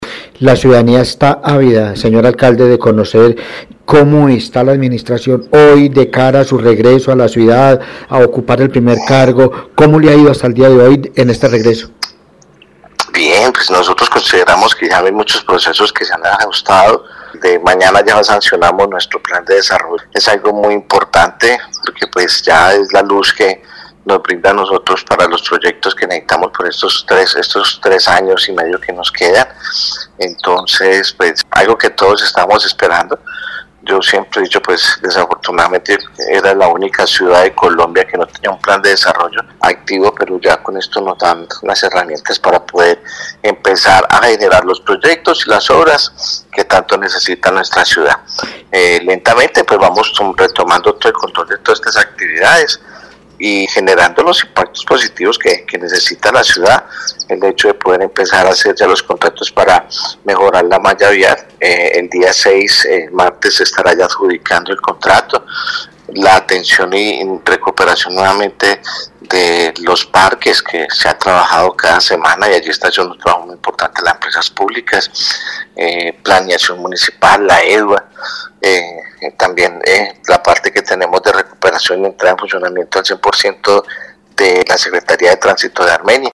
Periodismo Investigativo dialogó con el Alcalde de Armenia Dr. José Manuel Ríos Morales y sobre su salud explicó que: